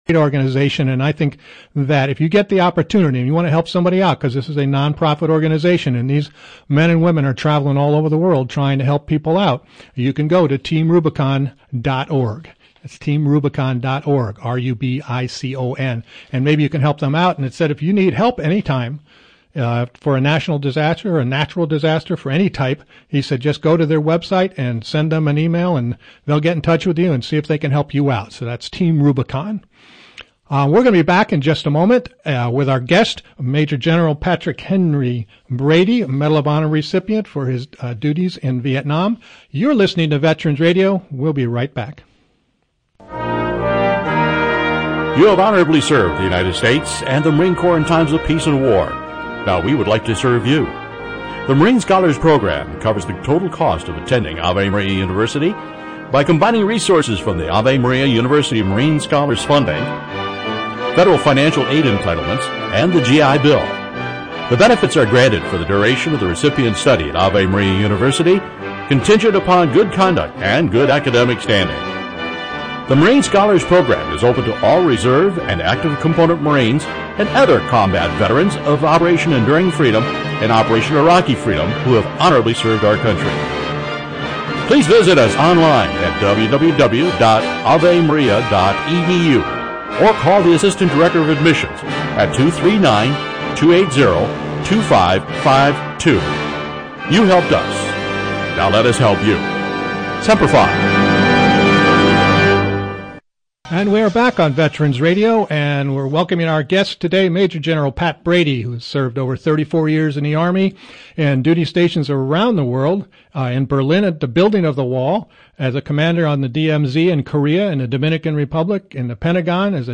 Don’t miss Major General Patrick Henry Brady’s interview this week on Veterans Radio!